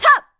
FSA_Link_SwordSlash212.7 KBMono, 16 KHz
FSA_Link_SwordSlash2.wav